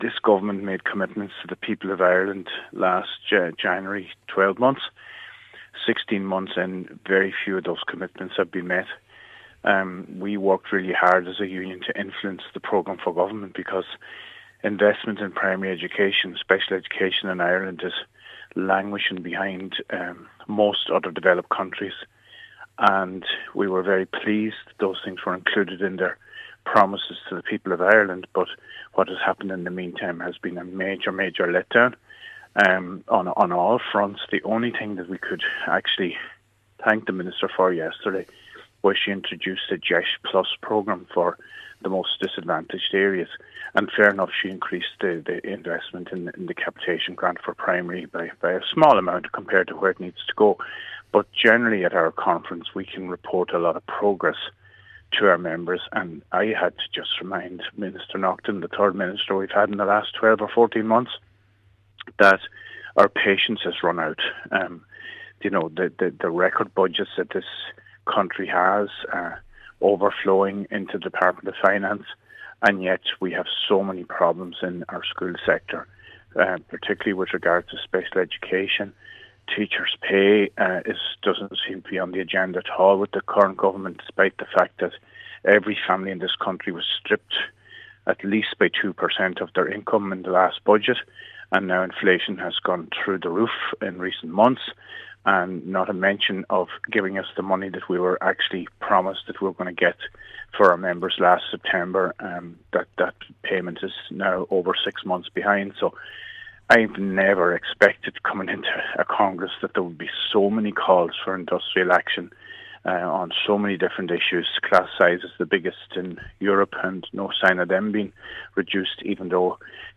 Speaking to Highland Radio News from the conference this morning, he said promises regarding long standing issues such as class sizes have not been honoured, and several pledges regarding pay and funding are still outstanding, months after they were to have been paid.